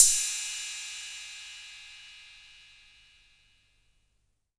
808CY_7_Tape.wav